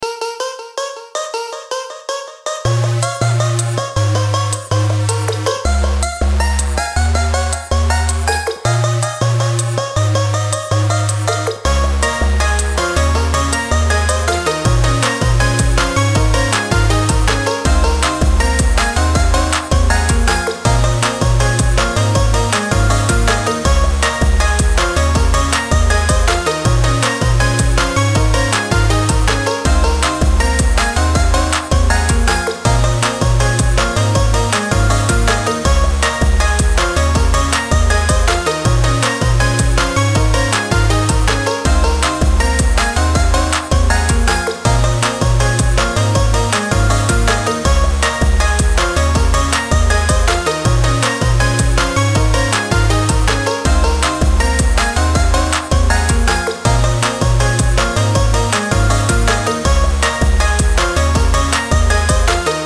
Home > Music > Pop > Bright > Running > Happy